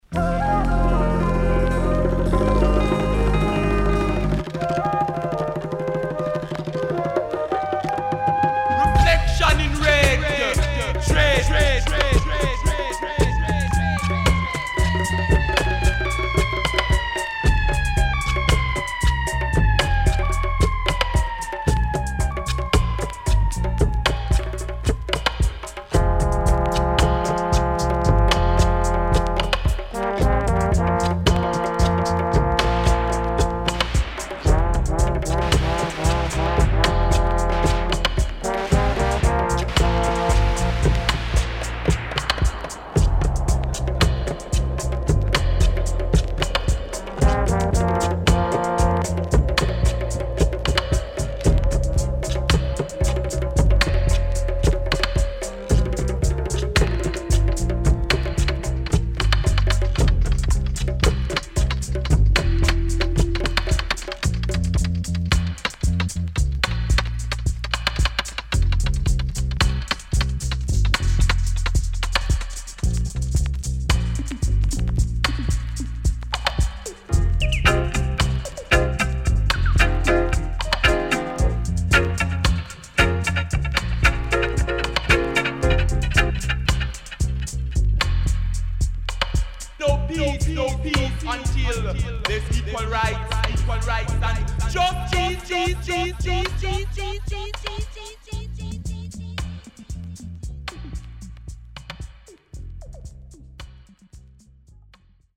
Dub Poet & Great Dubwise
SIDE A:中盤小傷により所々プチノイズ入ります。